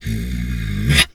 wolf_2_growl_03.wav